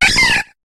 Cri de Granivol dans Pokémon HOME.